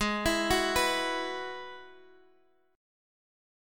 G#m7#5 Chord